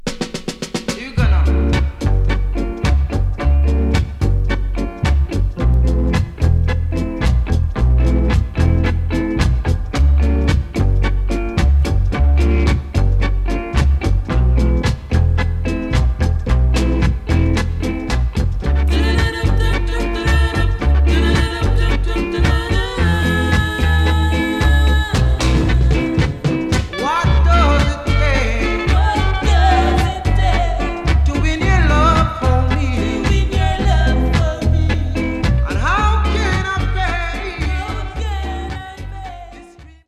Genre: Reggae, Rocksteady